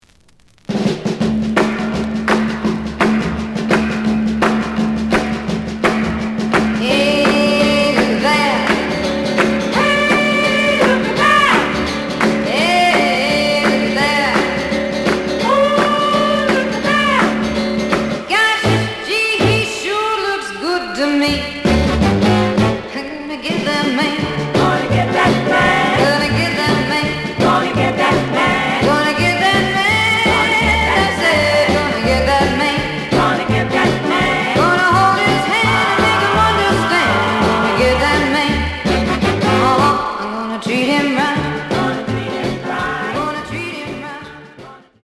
The audio sample is recorded from the actual item.
●Genre: Rock / Pop
B side plays good.